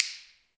Index of /90_sSampleCDs/Roland - Rhythm Section/PRC_Clap & Snap/PRC_Snaps